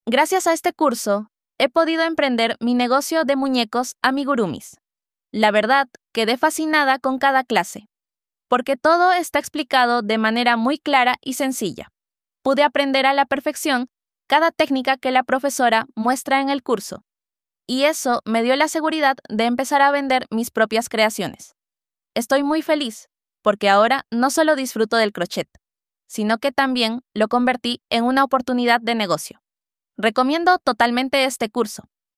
Nuestra alumana nos habla como desarrollo su habilidades para crear sus propios amigurumis para emprender para su negocio